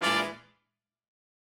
GS_HornStab-E7b2b5.wav